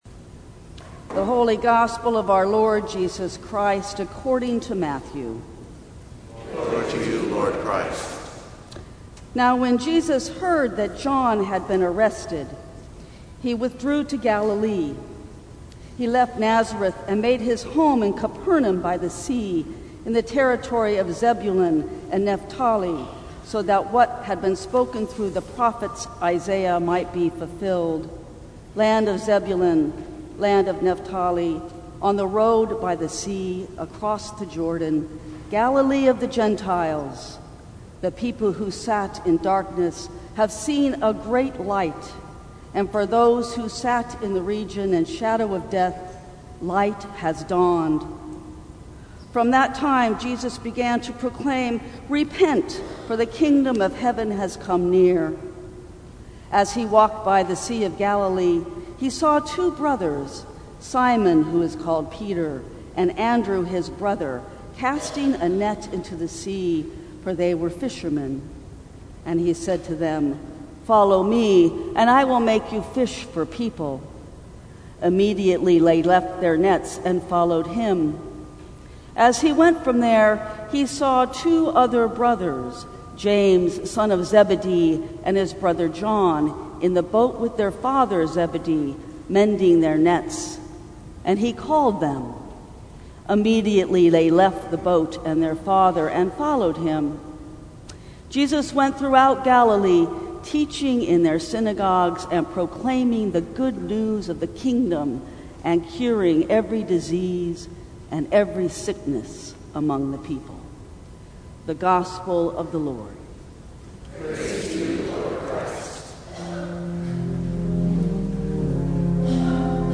Sermons from St. Cross Episcopal Church Community Jan 29 2020 | 00:13:20 1x 00:00 / 00:13:20 Subscribe Share Apple Podcasts Spotify Overcast RSS Feed Share Link Embed